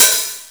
HI HAT OPEN.wav